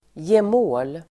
Ladda ner uttalet
gemål substantiv (högtidligt), consort [formal] Uttal: [jem'å:l] Böjningar: gemålen, gemåler Definition: maka el. make (till kunglig person) (wife, husband or companion (of a member of the royal family))